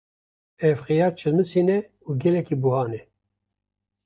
Pronounced as (IPA) /xɪˈjɑːɾ/